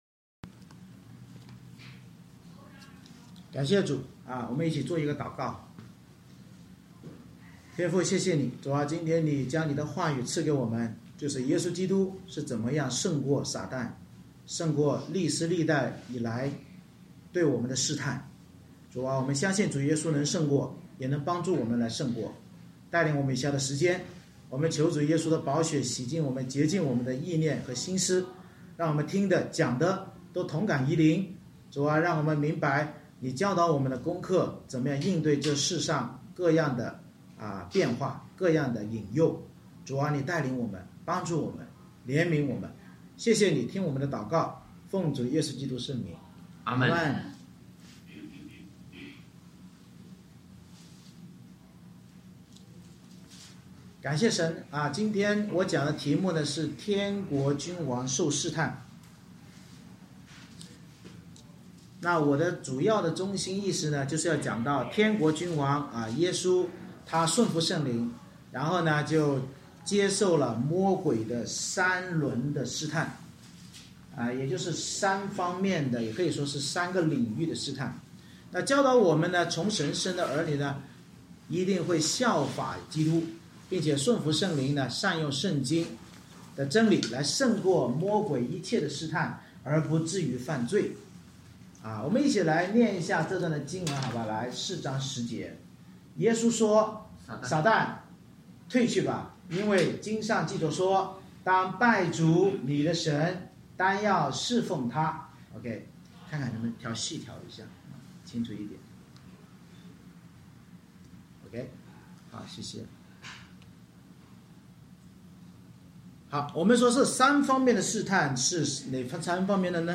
马太福音4:1-11 Service Type: 主日崇拜 天国君王耶稣顺服圣灵接受魔鬼三轮试探，教导我们从神生的儿女必会效法基督顺服圣灵善用圣经真理胜过魔鬼一切试探而不至犯罪。